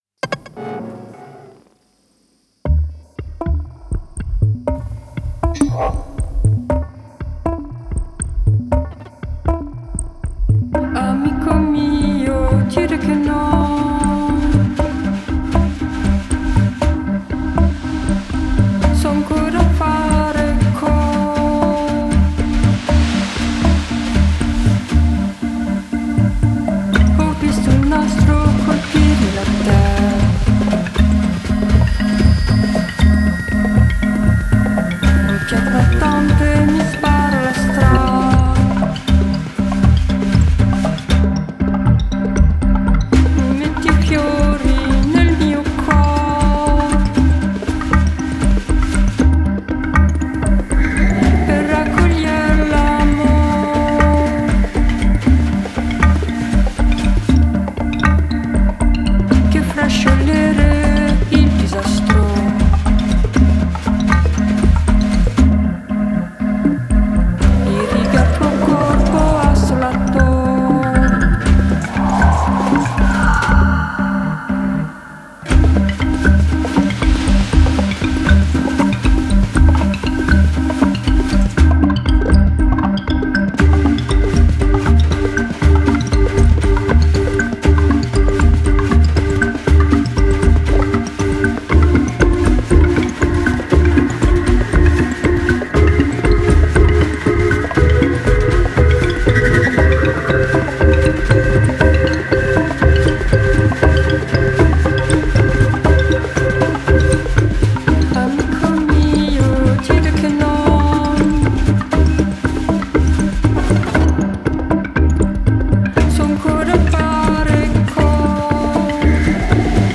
chante d’une voix mélancolique